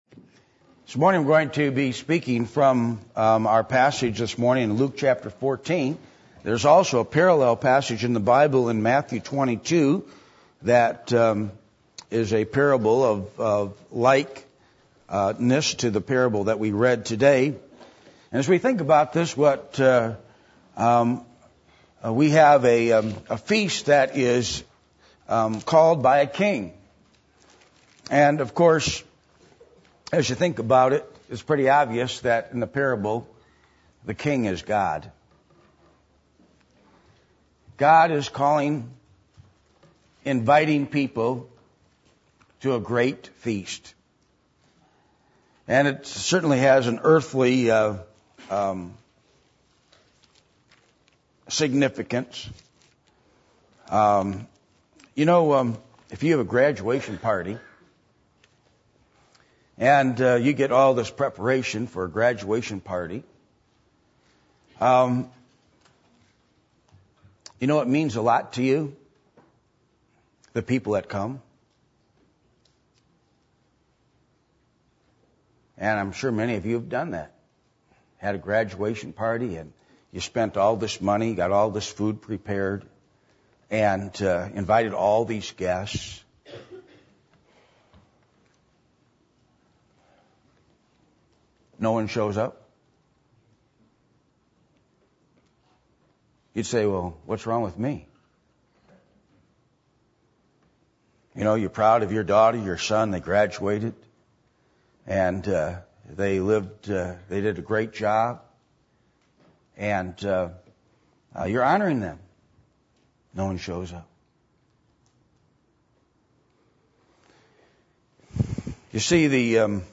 Passage: Luke 14:15-24 Service Type: Sunday Morning